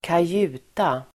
Ladda ner uttalet
kajuta substantiv, cabin [on a boat] Uttal: [²kaj'u:ta] Böjningar: kajutan, kajutor Synonymer: hytt Definition: bästa rum på mindre båt cabin substantiv, hytt [sjöterm], kajuta [bästa rum på mindre båt], aktersalong
kajuta.mp3